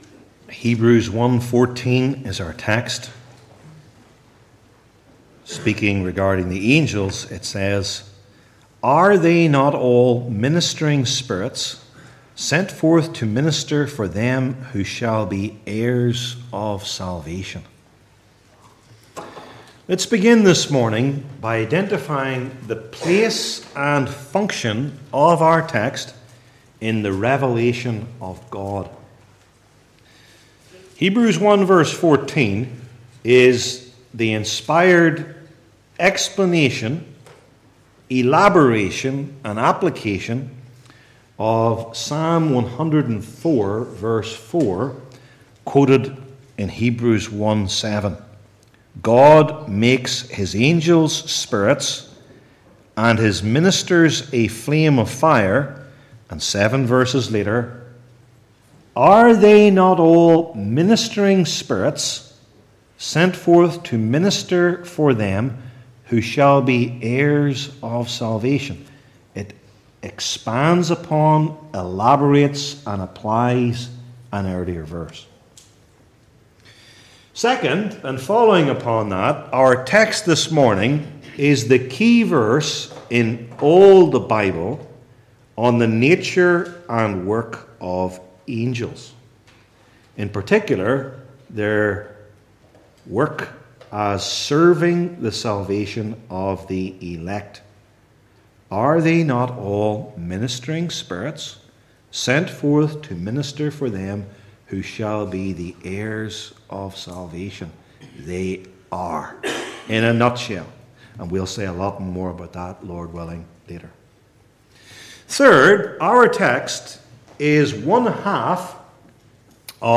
Hebrews 1:14 Service Type: New Testament Sermon Series I. Office II.